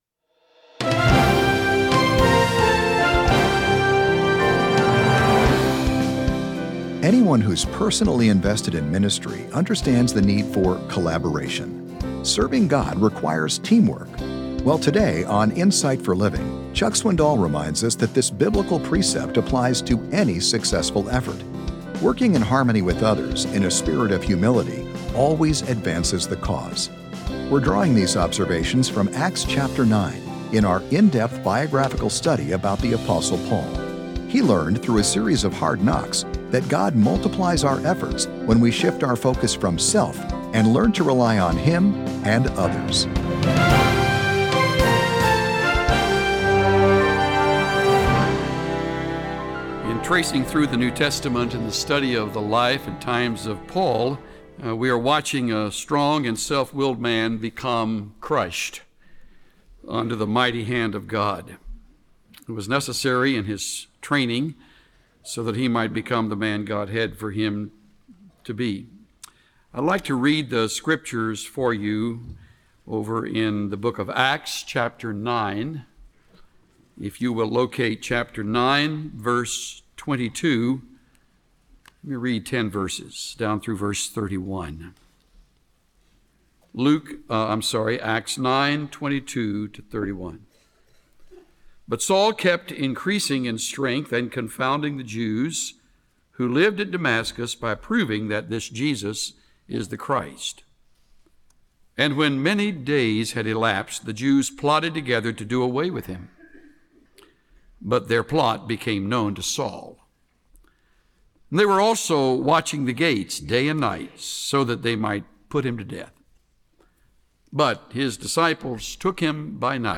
1 The Mystery of Regeneration - Jul. 21 Sermon 27:00